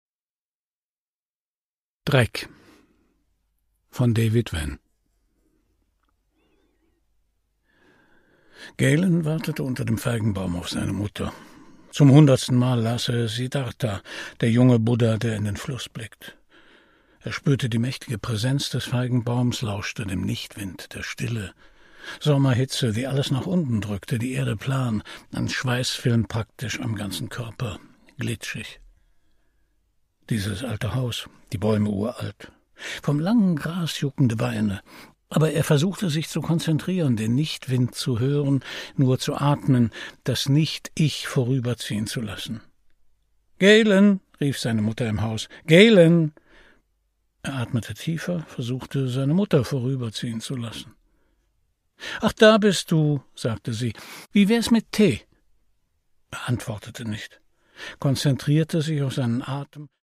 Produkttyp: Hörbuch-Download
Gelesen von: Christian Brückner